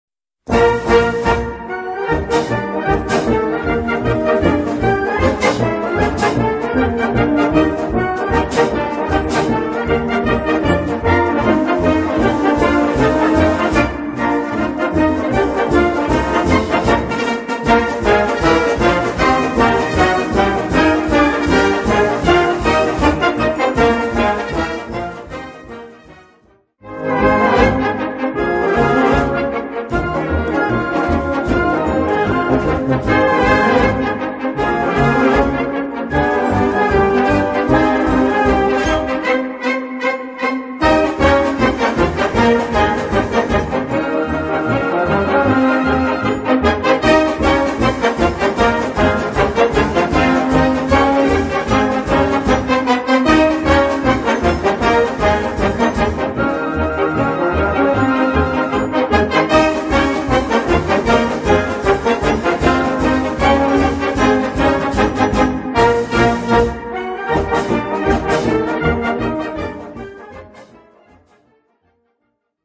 Gattung: Polka schnell
Besetzung: Blasorchester